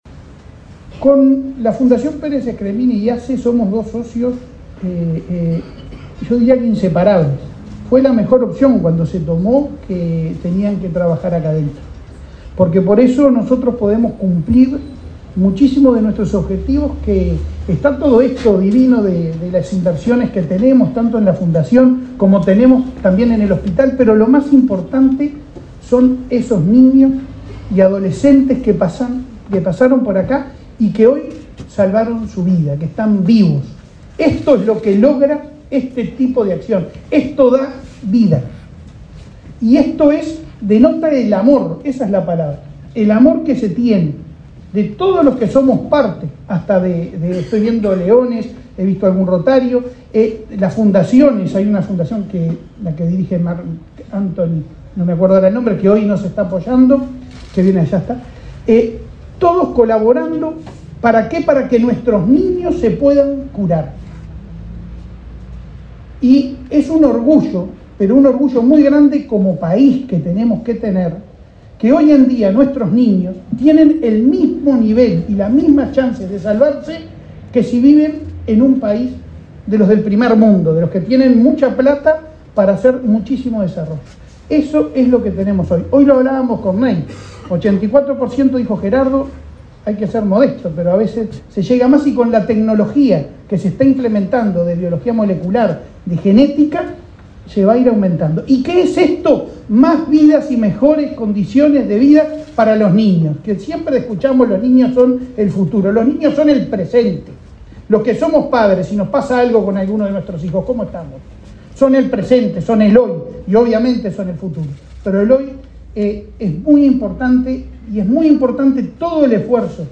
Palabras del presidente de ASSE, Leonardo Cipriani